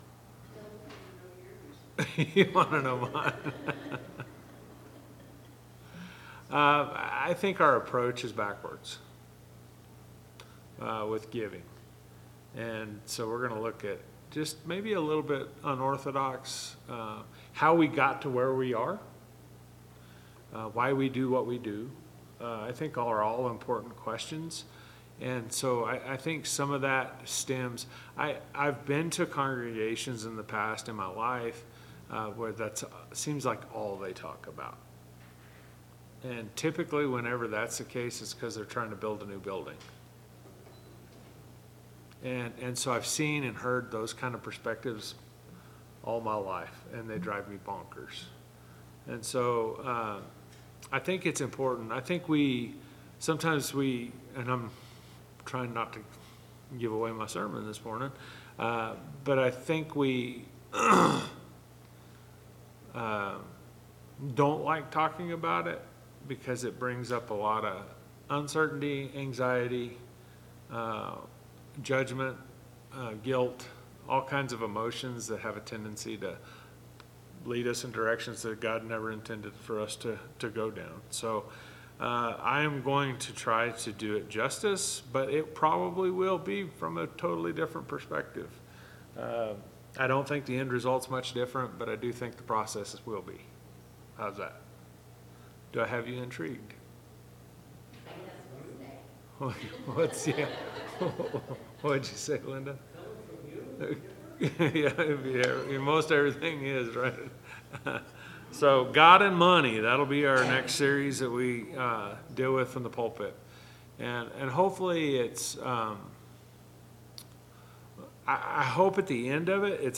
Bible Class 08/10/2025 - Bayfield church of Christ